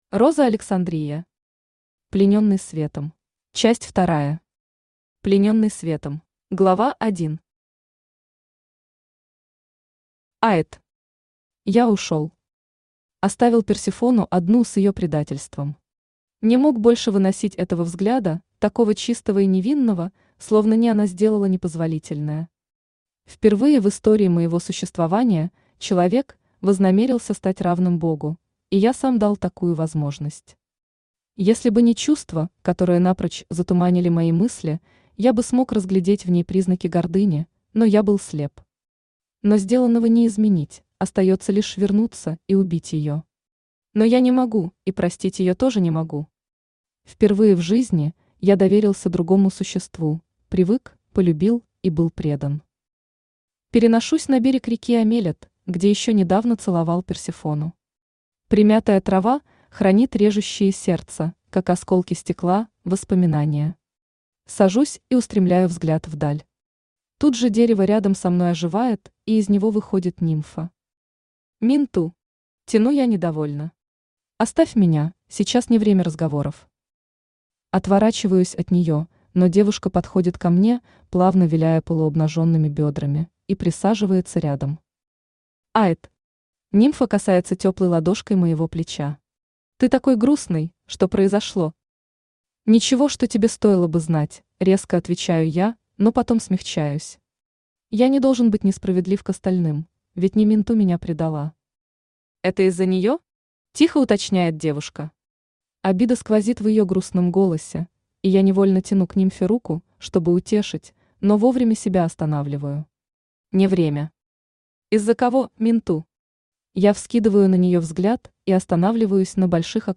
Aудиокнига Плененный светом Автор Роза Александрия Читает аудиокнигу Авточтец ЛитРес.